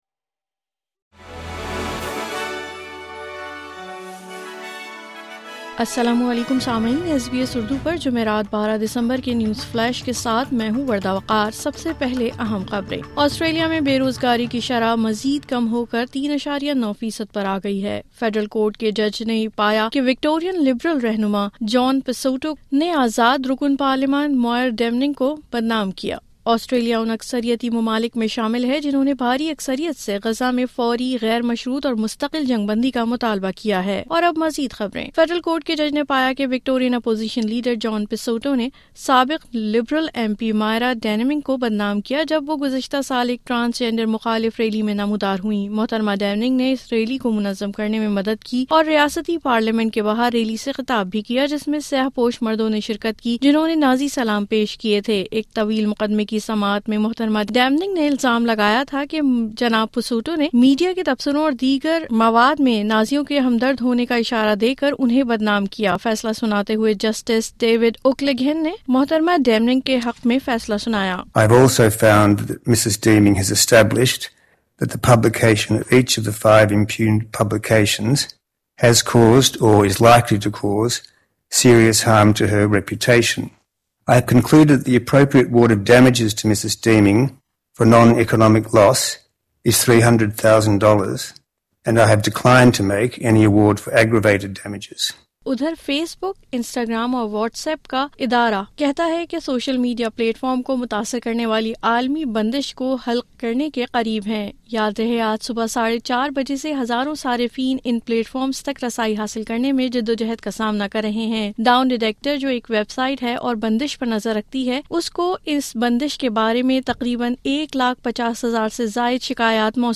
اردو نیوز فلیش:12 دسمبر 2024